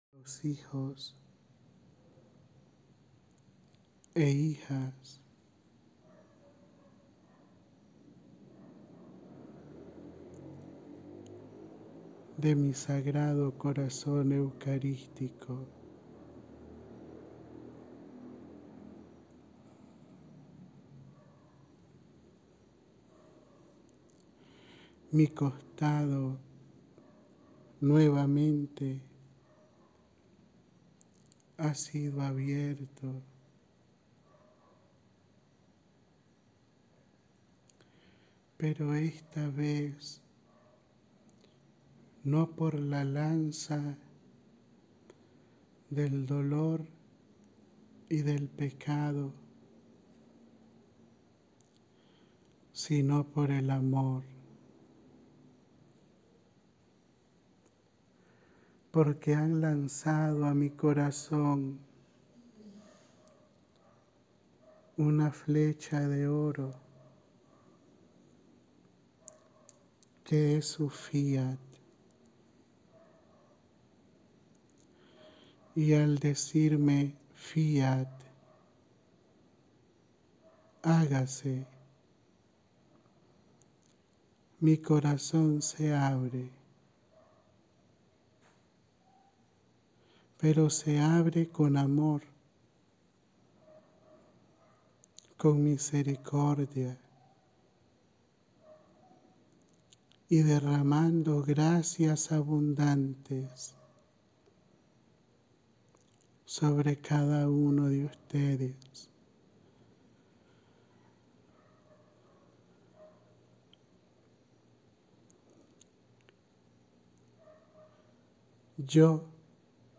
(No encerramento do primeiro dia do Retiro Sacerdotal Mundial em Morelos, Cuernavaca, México)
Audio da Mensagem